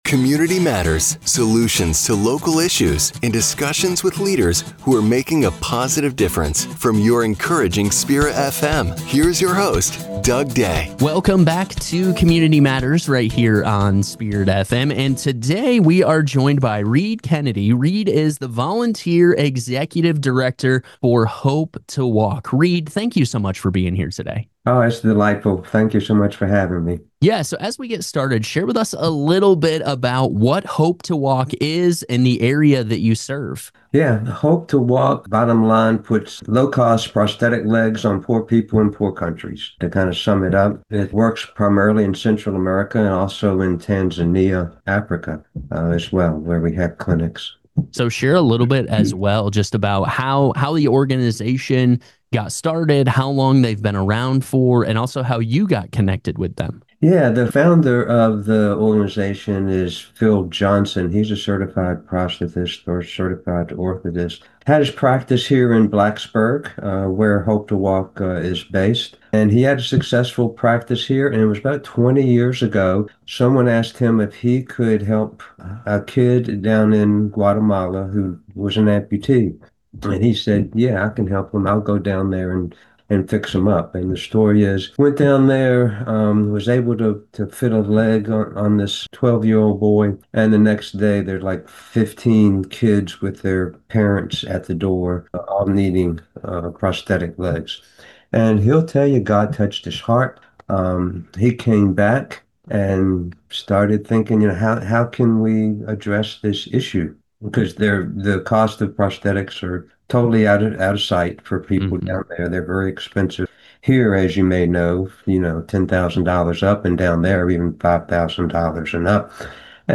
An Interview with Volunteer Executive Director